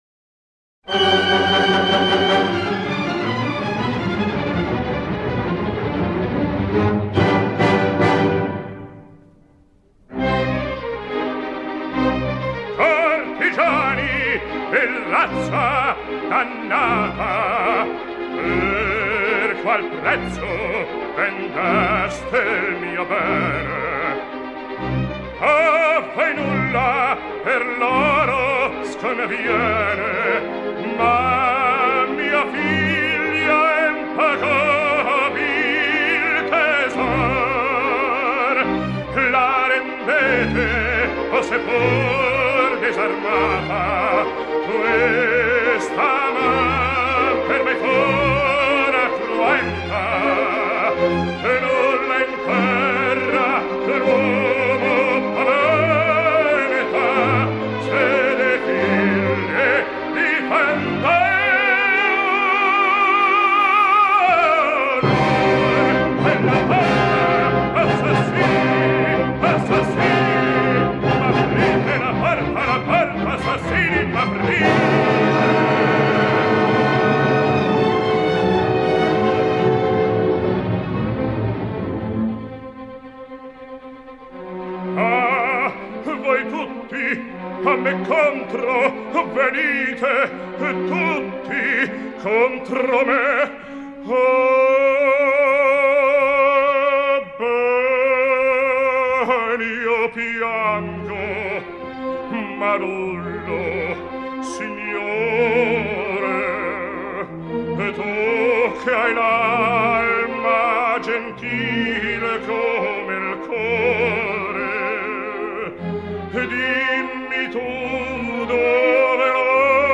Главная Аудио Баритоны Этторе Бастианини